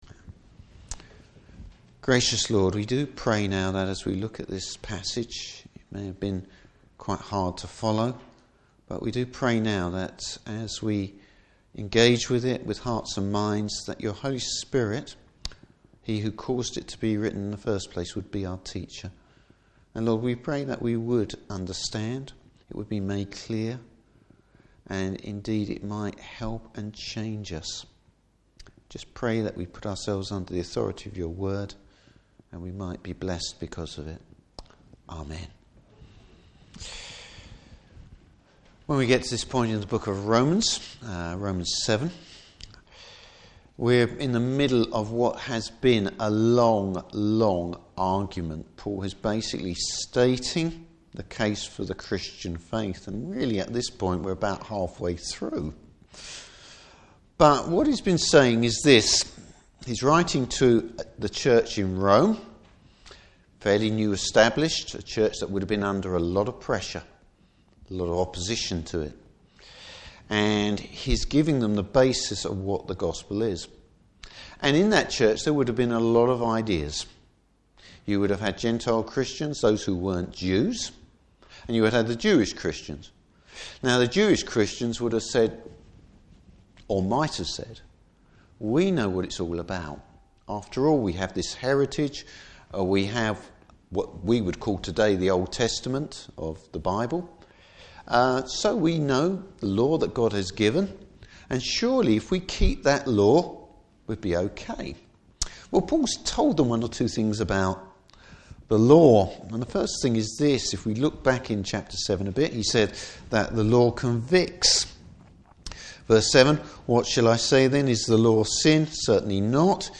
Service Type: Morning Service The struggle with sin!